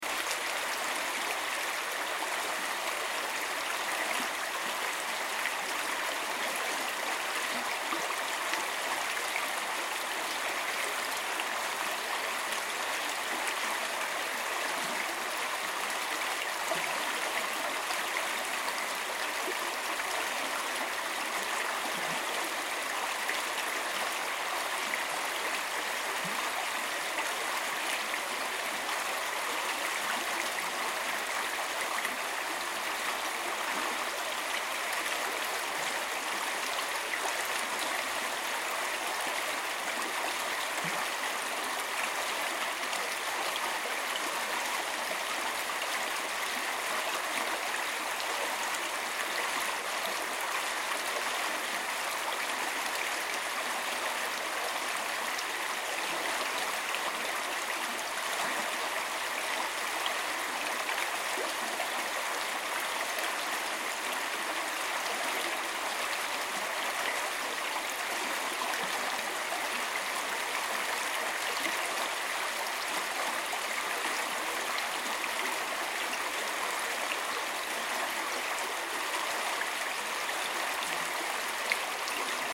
I did, however, take some audio recordings of the water running under a bridge, the first one that goes over Montana Creek.
water sounds exhibit A (MP3)